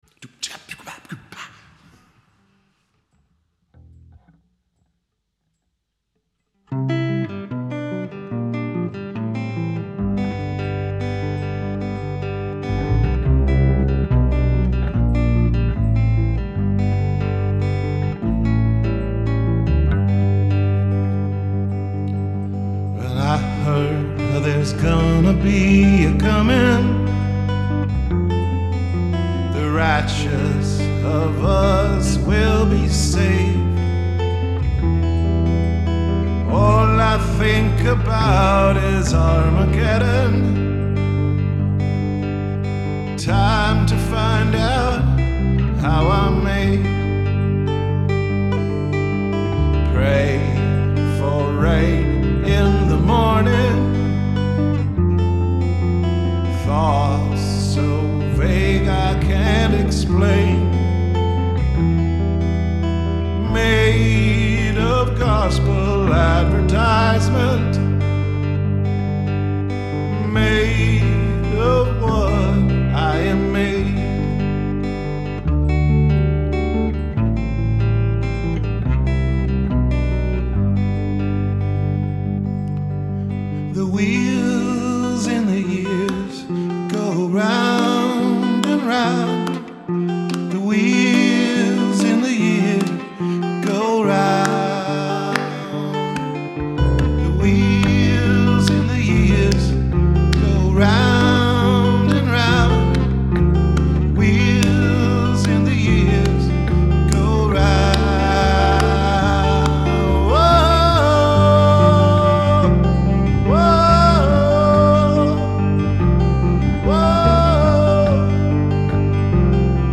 Rehearsals 27.2.2012